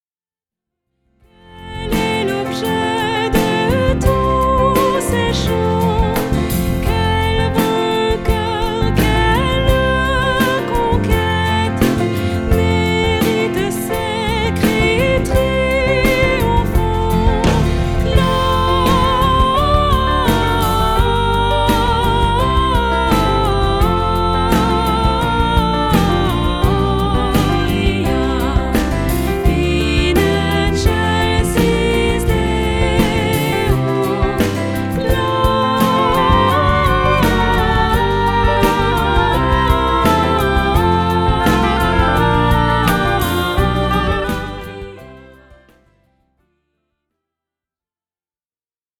Les grands classiques de Noël
voix cristalline